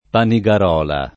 [ pani g ar 0 la ]